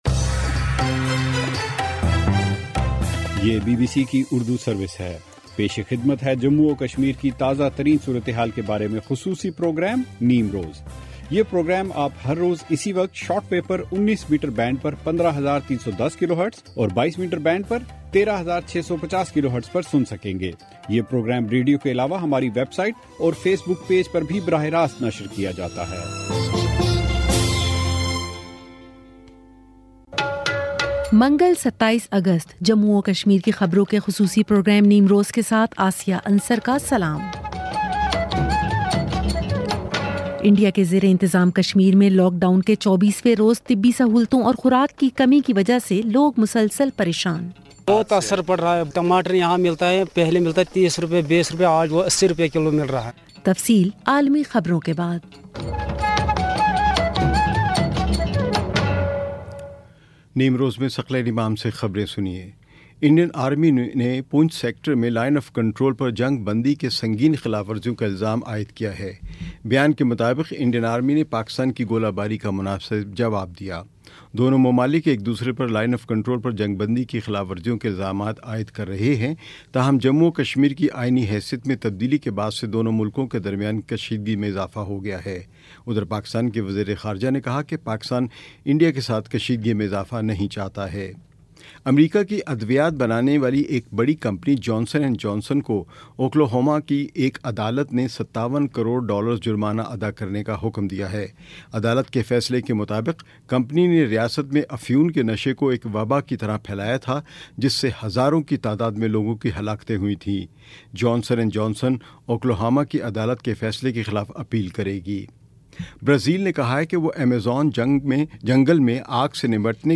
بی بی سی اردو سروس سے جموں اور کشمیر کی خبروں کا خصوصی پروگرام نیم روز